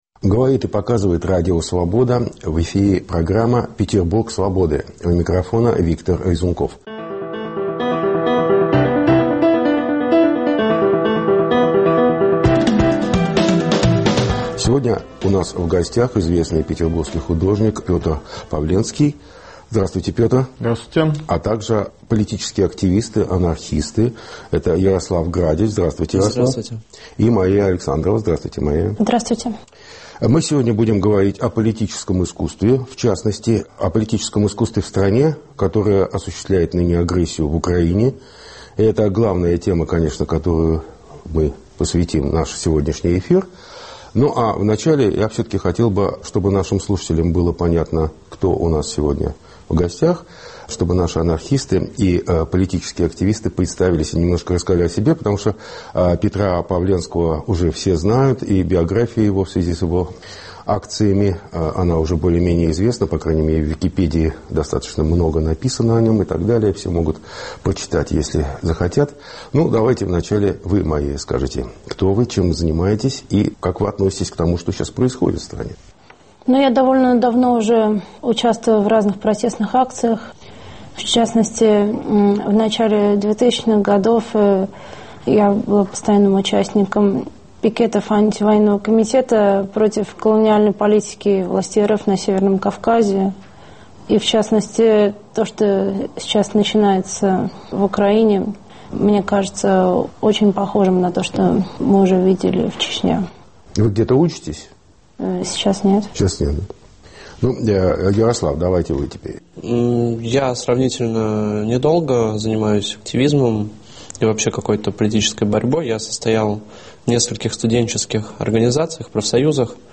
В разговоре принимают участие художник-акционист Петр Павленский и его сподвижники